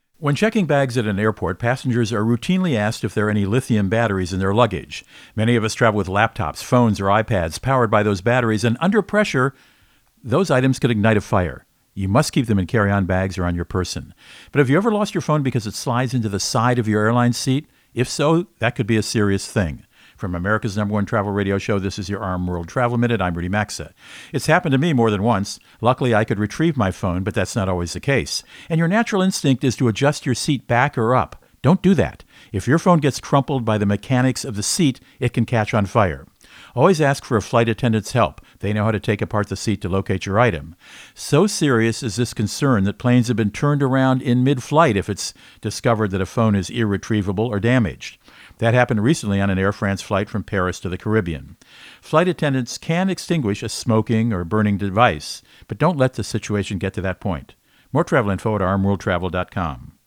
Co-Host Rudy Maxa | Lithium Batteries and Their Danger